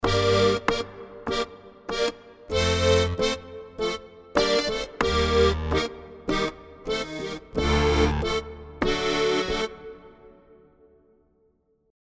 Harmochord – Musette Octaves: